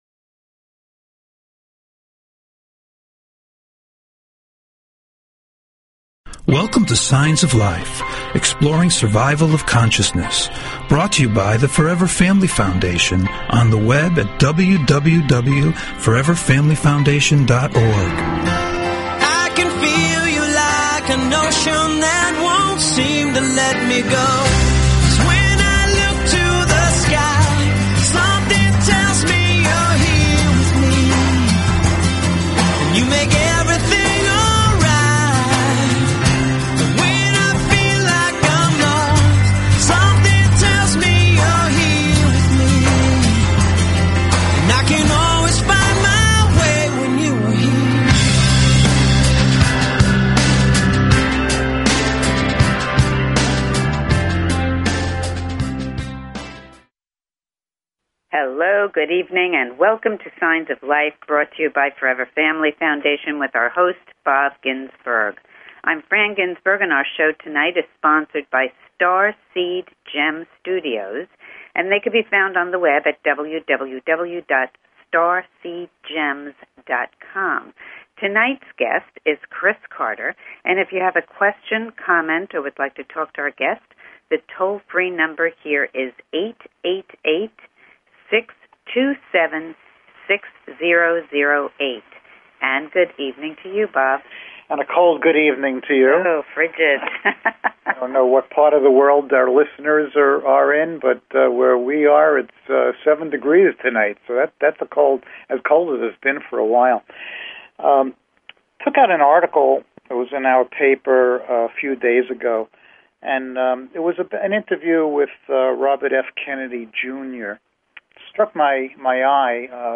Talk Show Episode, Audio Podcast, Signs_of_Life and Courtesy of BBS Radio on , show guests , about , categorized as
Call In or just listen to top Scientists, Mediums, and Researchers discuss their personal work in the field and answer your most perplexing questions.